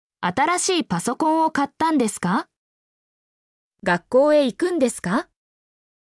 mp3-output-ttsfreedotcom-2_uZctrxAv.mp3